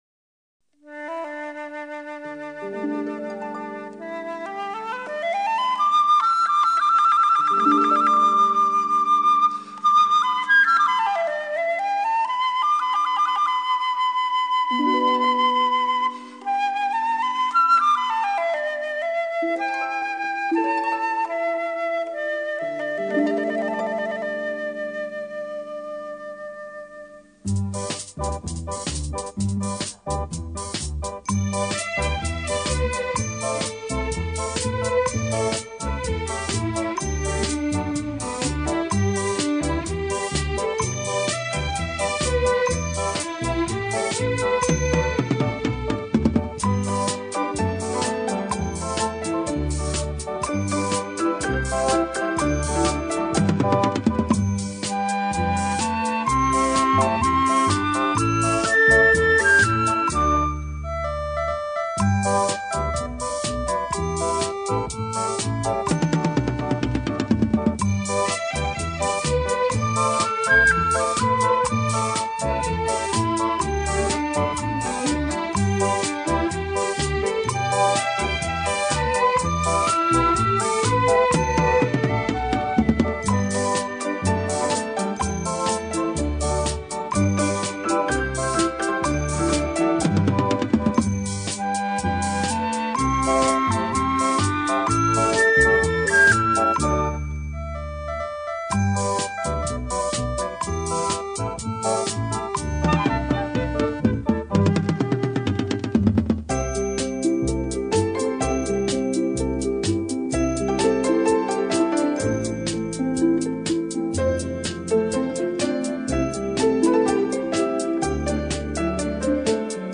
江南乐曲经典 精心编曲 用心制作
琵琶